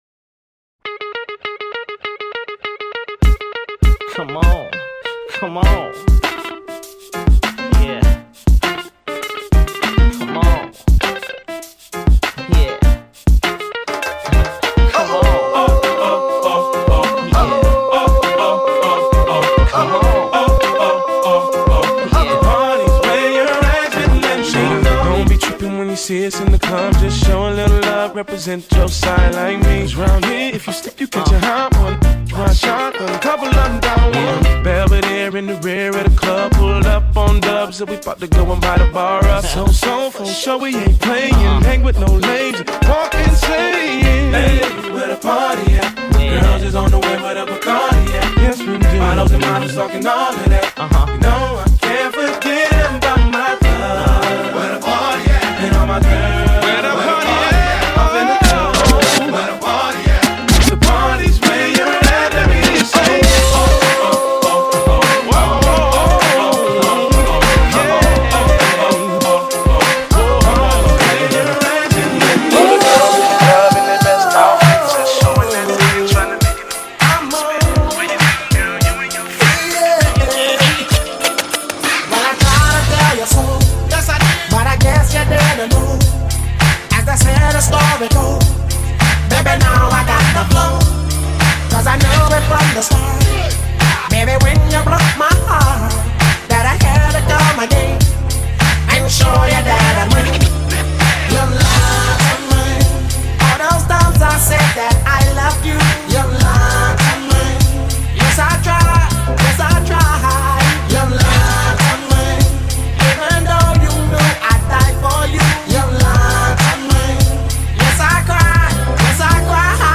From £719 + travel | Wedding DJ